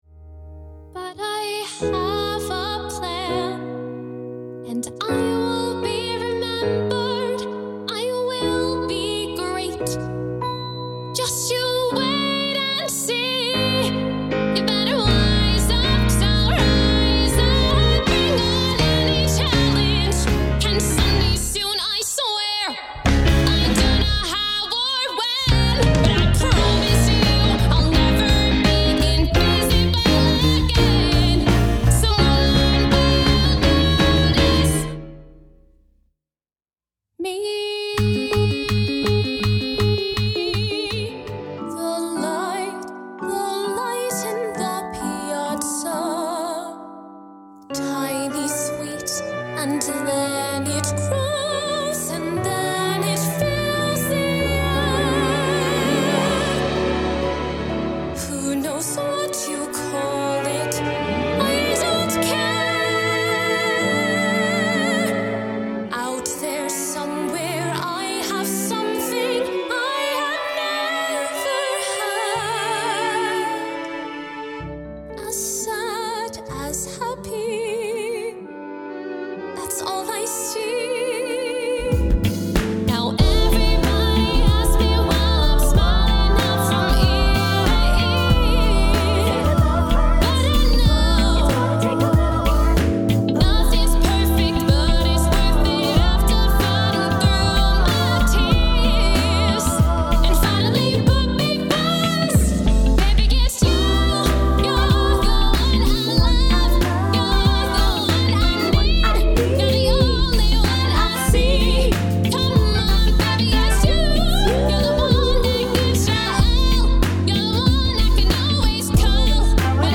Musical Theatre
Iain Gallacher Photography Sings: Soprano C3 to B5 (belt to Bb4) Dances